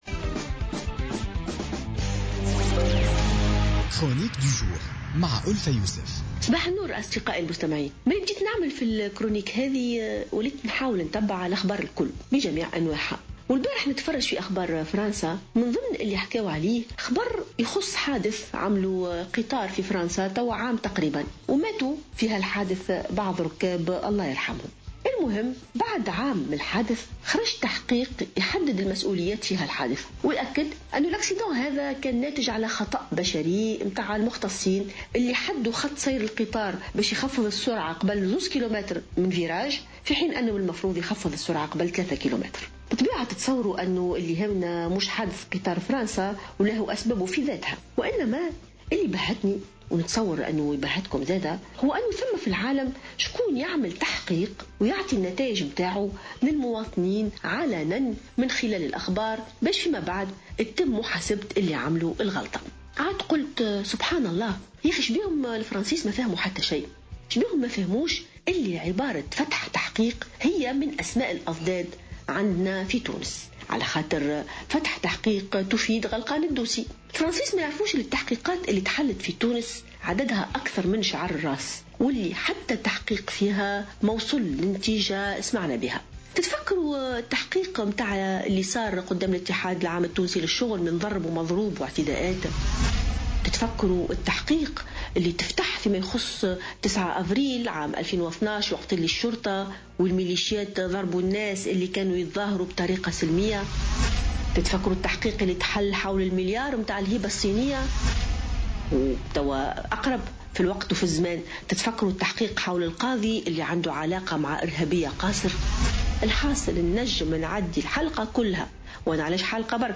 انتقدت الجامعية ألفة يوسف في افتتاحية اليوم الأربعاء تعامل الحكومات المتعاقبة في تونس مع كل التحقيقات التي تم فتحها في مختلف القضايا.